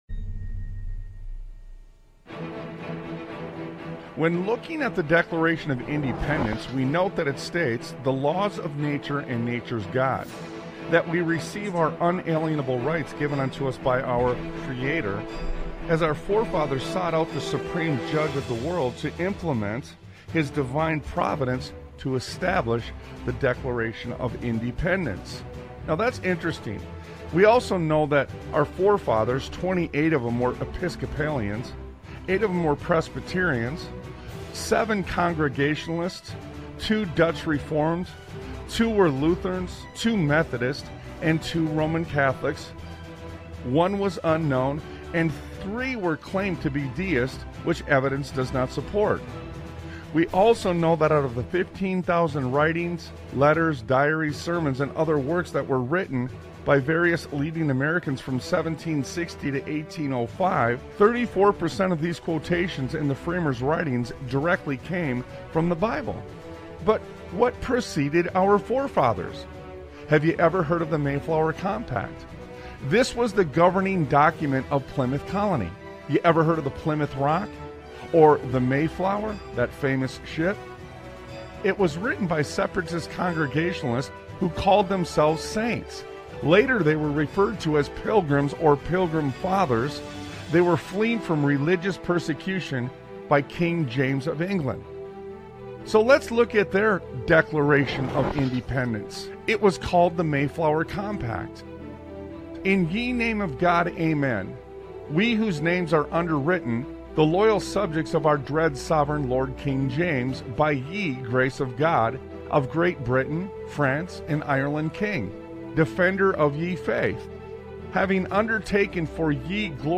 Talk Show Episode, Audio Podcast, Sons of Liberty Radio and Forefathers Admonished Us To "Study The Past" on , show guests , about Forefathers Admonished Us To Study The Past,The Blueprint of Liberty,Studying the Past to Secure the Future,The Matrix of Liberty,justice,Recidivism,Judicial Accountability,administrative law,Holding the Ten Commandments,Scroll of Revelation, categorized as Education,History,Military,News,Politics & Government,Religion,Christianity,Society and Culture,Theory & Conspiracy